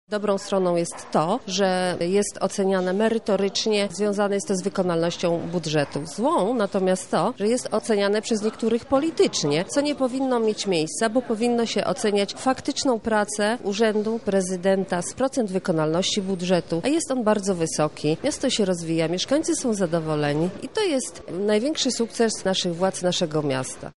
– Każde absolutorium ma dobre i złe strony – mówi Marta Wcisło, radna z Platformy Obywatelskiej.